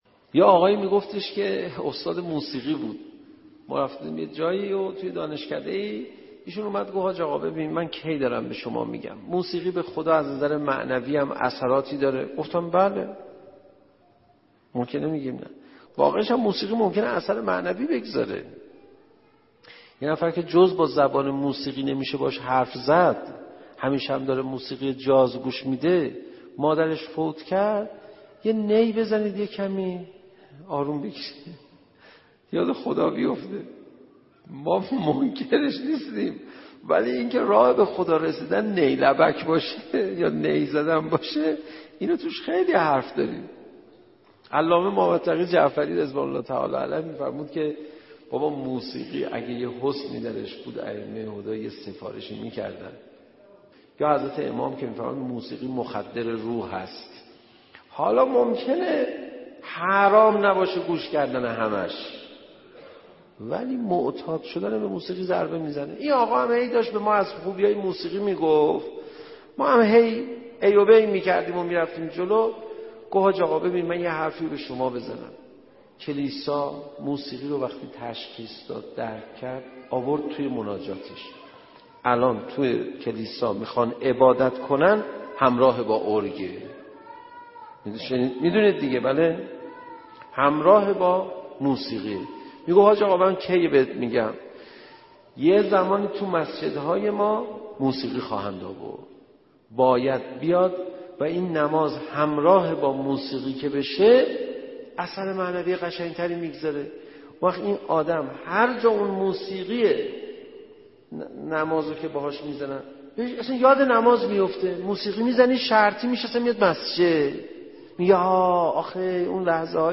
منبر دو دقیقه ای/دعایی که منافقانه است